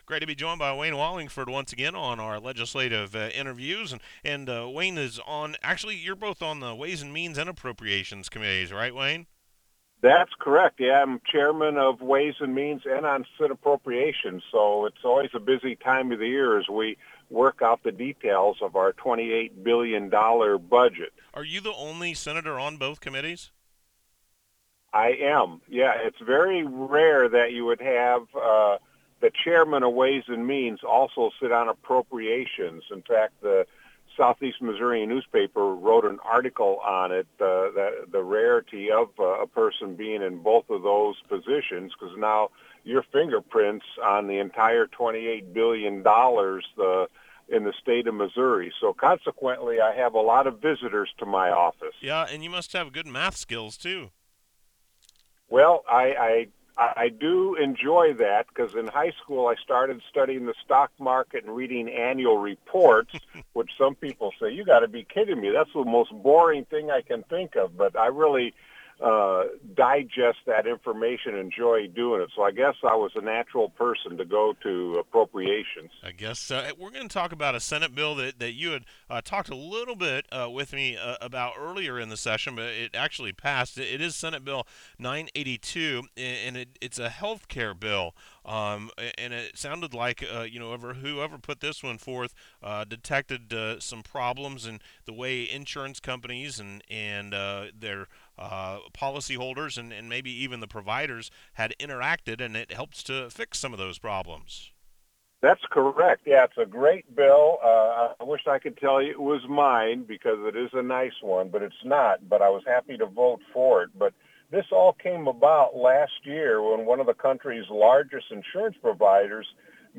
Interview with 27th District Senator Wayne Wallingford 4-27-18 - My Mo Info